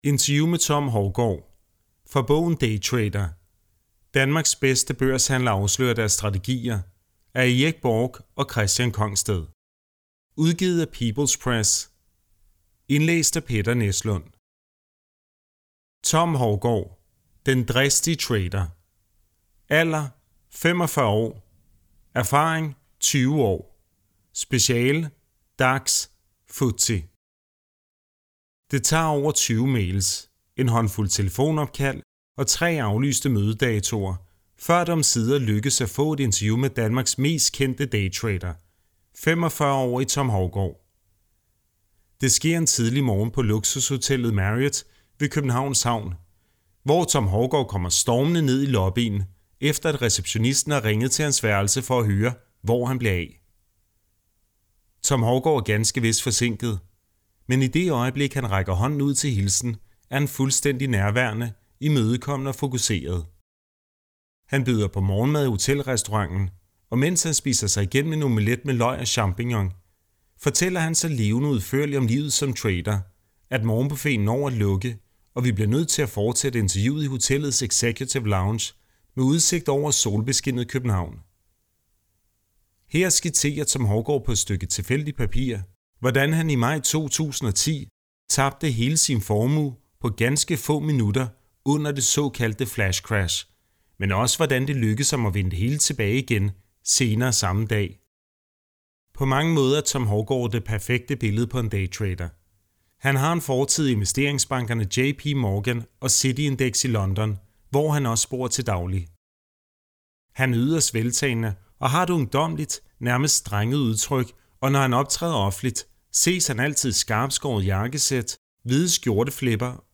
Lydbog
download hele oplevelsen og et interview